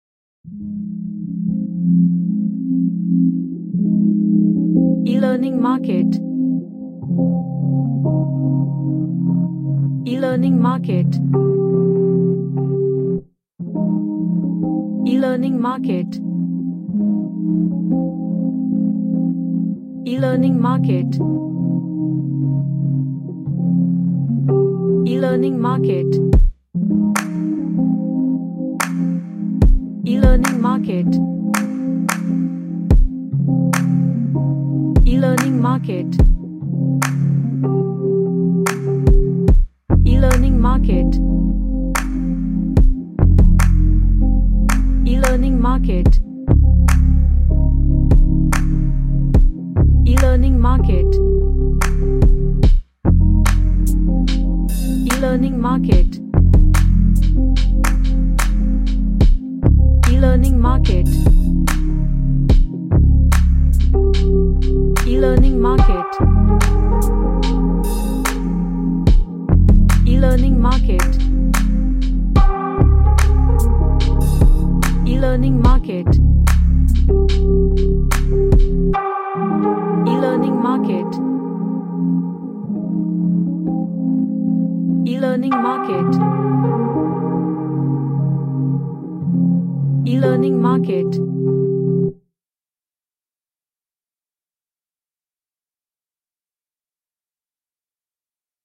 An ambient type R&B pop track
Gentle / Light